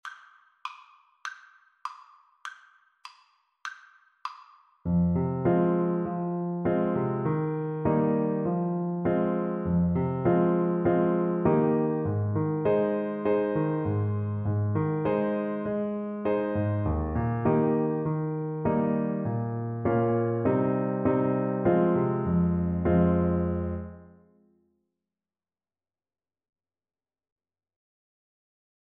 2/4 (View more 2/4 Music)
Moderato